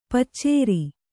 ♪ paccēri